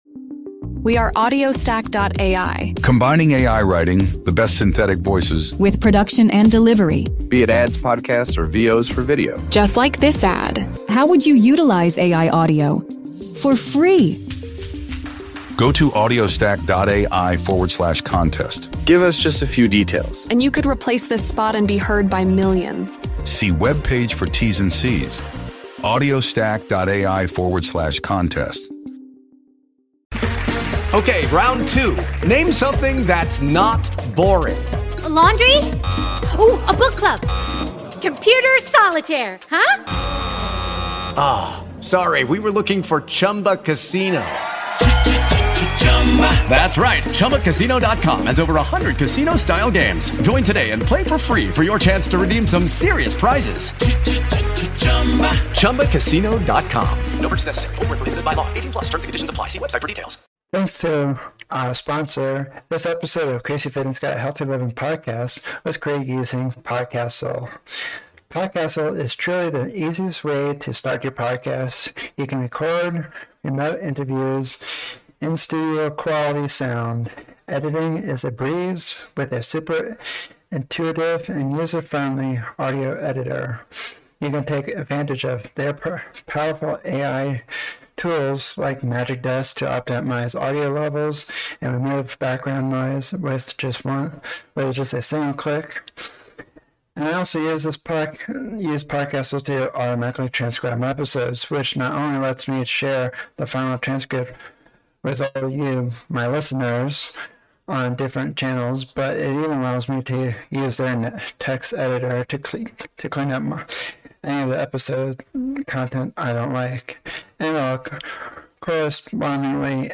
Discover the proven strategies you need for personal growth and lasting fulfillment in this exclusive podcast trailer! We're sharing actionable tips, expert insights, and secrets to help you crush your goals and unlock your full potential. Don't wait—this transformative interview is packed with motivation and practical advice you can start using today.